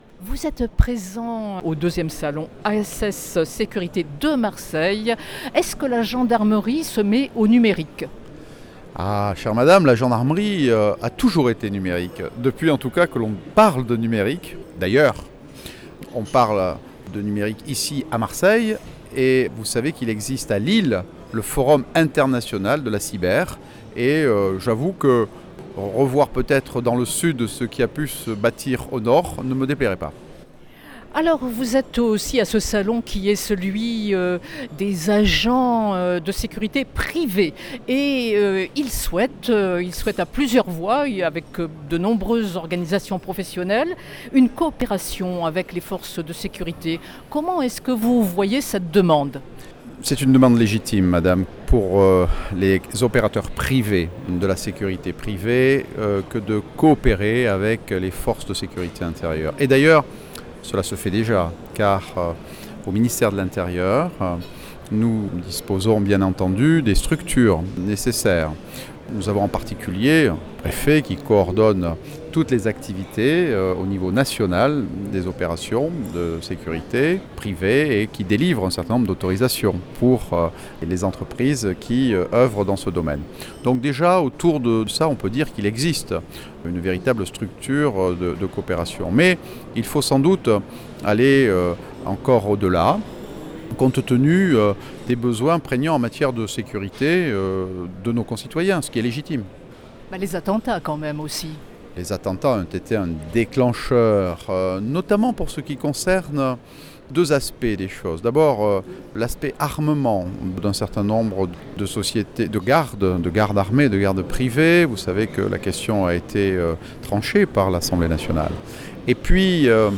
AccesSecurity, le Salon Méditerranéen de la sécurité globale, dont la cybersécurité, a ouvert ses portes, ce mercredi 29 mars pour 3 journées au Parc Chanot à Marseille.